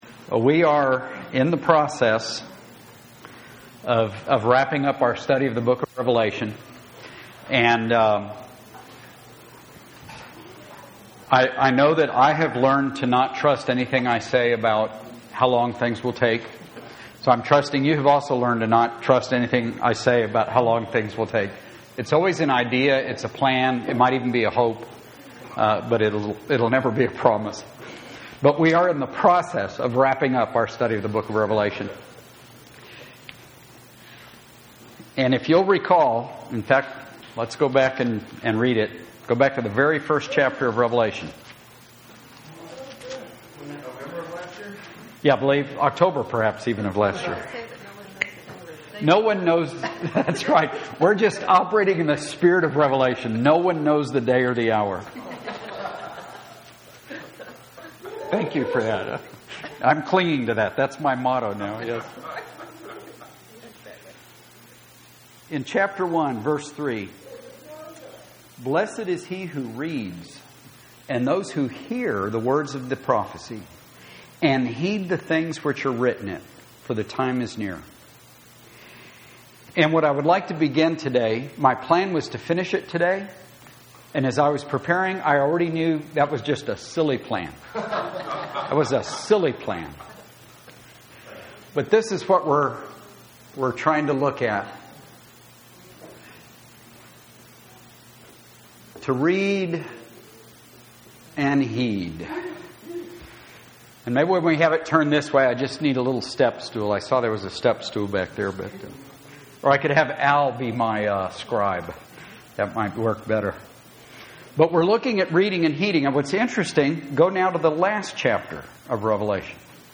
Grace Bible Fellowship, Bastrop Texas
Sermons